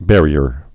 (bĕrē-ər)